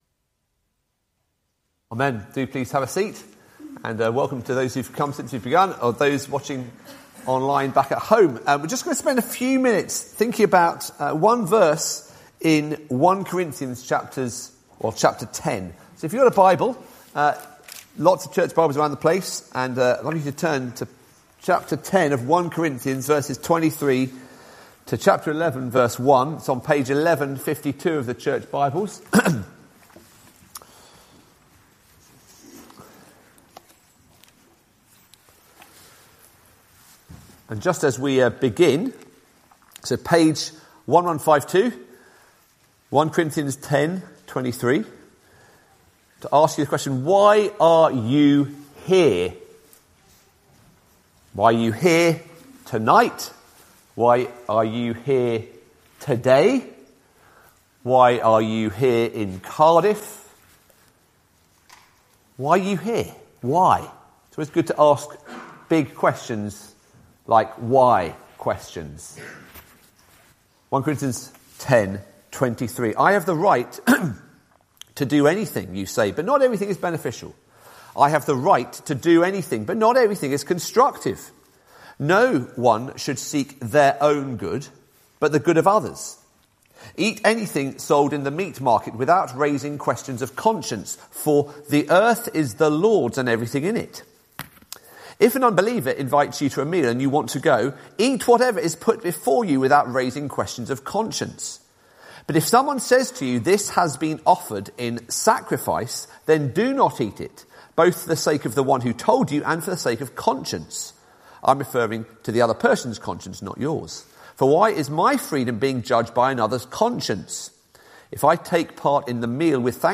Preacher for this Service
Sermon Online: